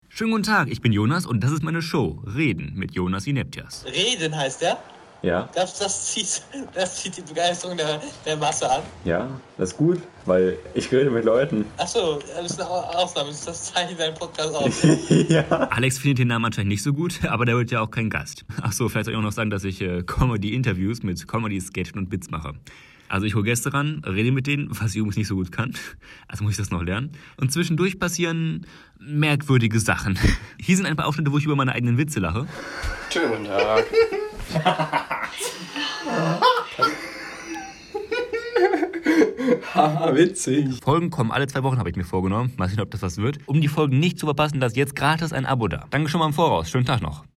Comedy Interviews mit Gästen und Blödsinn